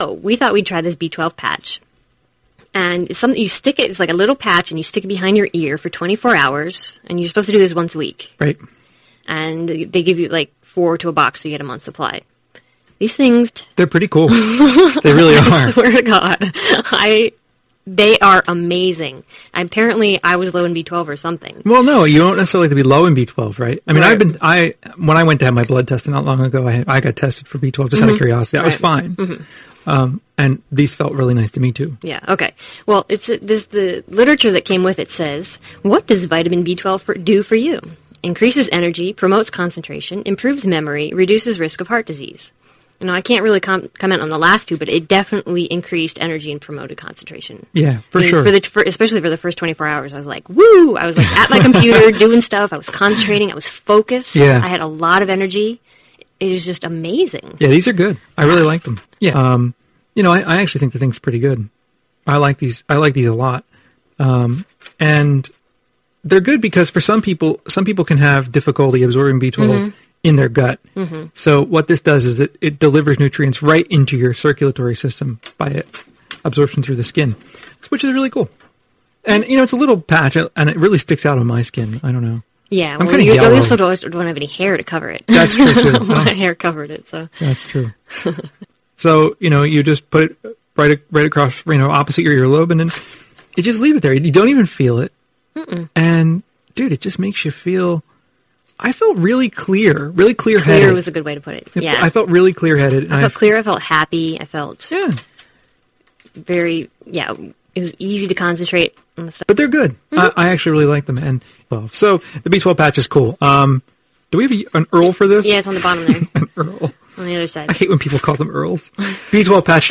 Listen to this talk radio show review of the Vitamin B12 Patch: b12 patch review
Talk radio show reviews the Vitamin B12 Patch